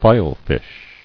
[file·fish]